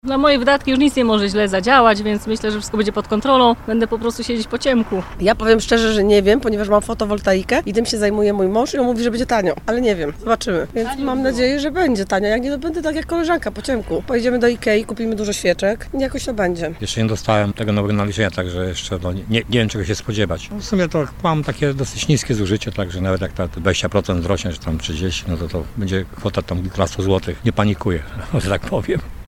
Zapytaliśmy mieszkańców, czy już odczuwają podwyżki cen.